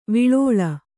♪ viḷōḷa